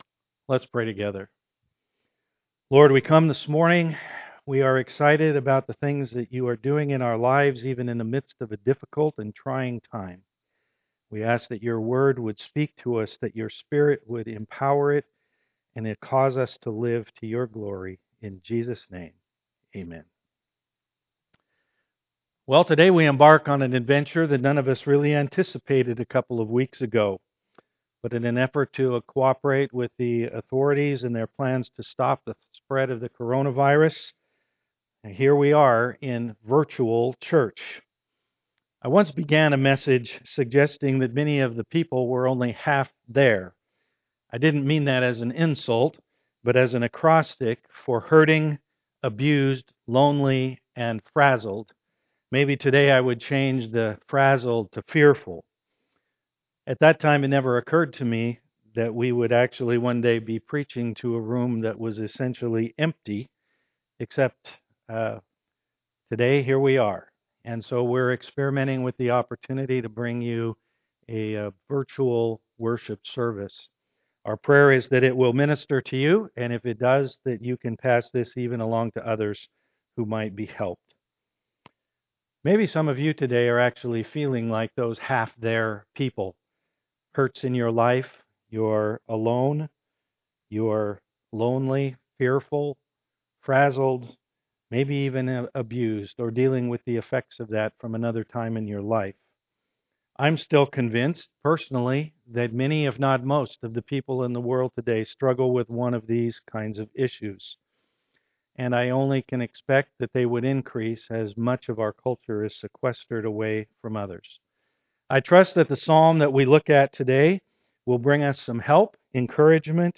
Psalm 121 Service Type: am worship Welcome to virtual church.